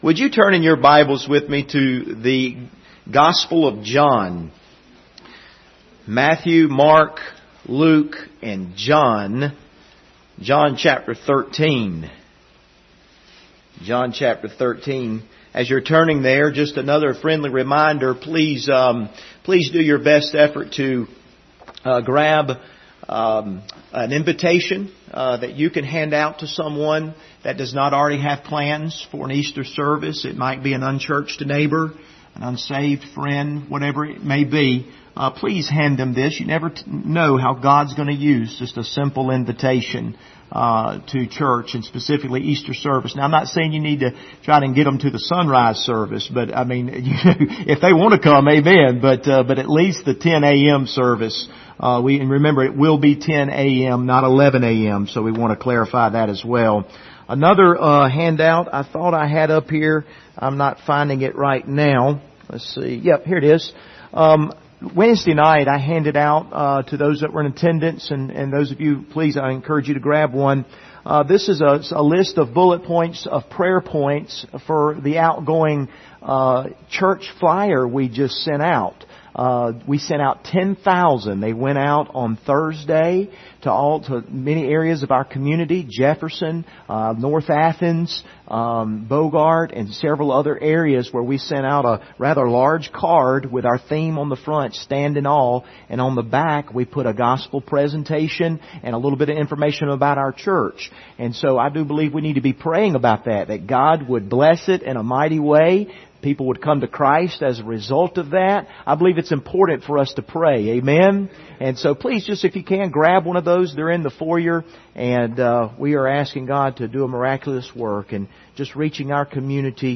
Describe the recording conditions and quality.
Passage: John 13:1-7 Service Type: Sunday Morning